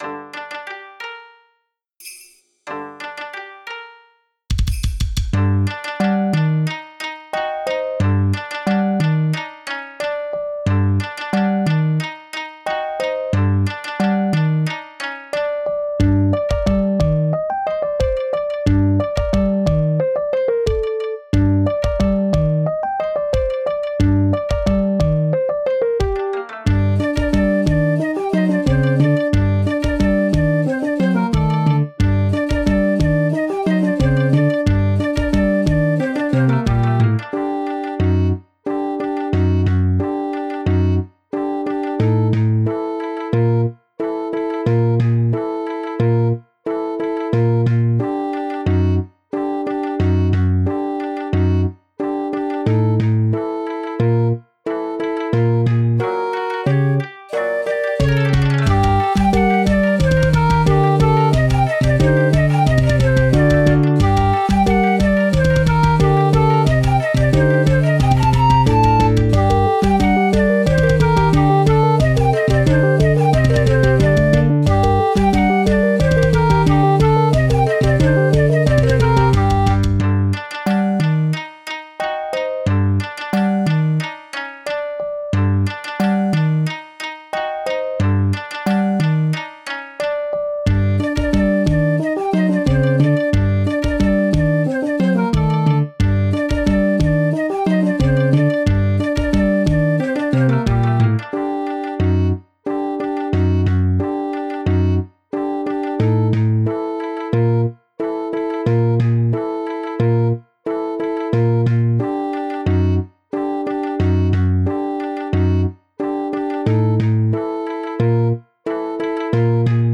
和風
ホラーなシーンに使える怖いBGMです。 緊張感を与えたいときにも使えます。
和楽器に少しシンセサイザーを足してかっこよくしてみました